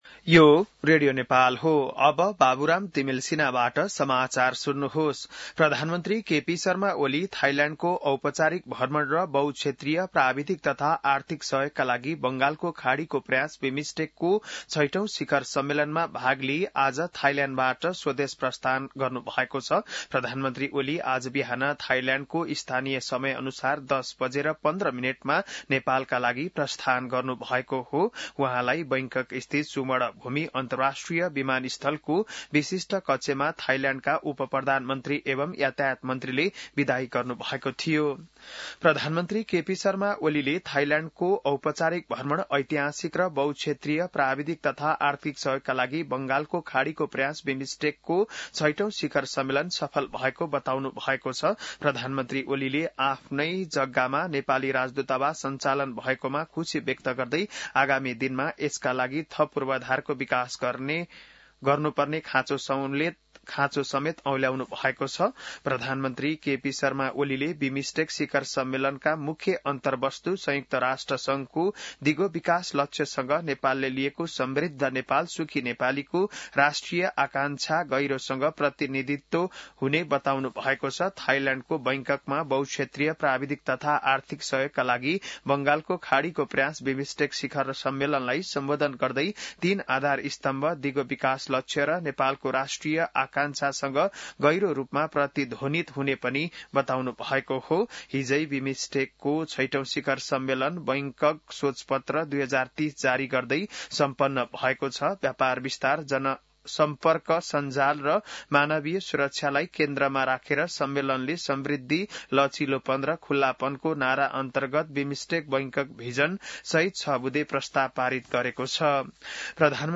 बिहान १० बजेको नेपाली समाचार : २३ चैत , २०८१